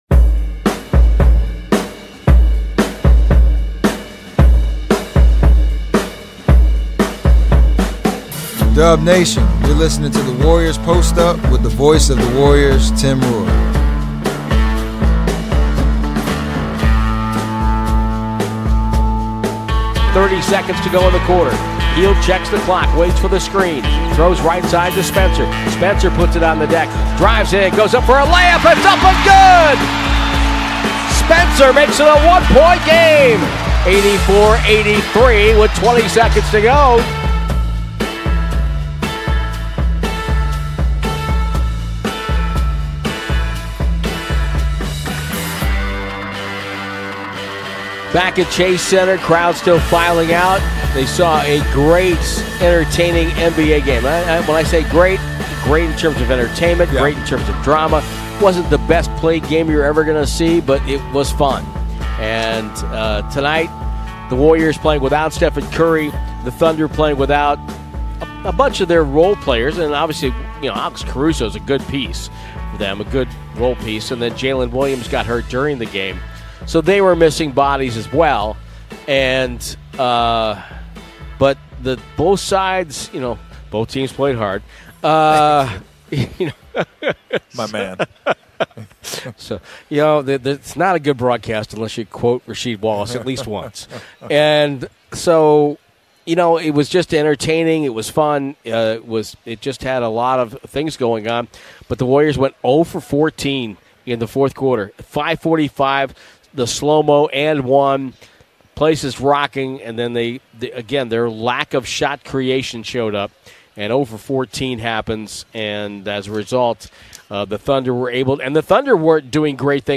Official Golden State Warriors shows with interviews and analysis direct from the team, focusing on topics from in and around the NBA